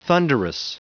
Prononciation du mot thunderous en anglais (fichier audio)
Prononciation du mot : thunderous